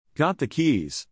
For most other types of questions, use a falling intonation at the end of the sentence.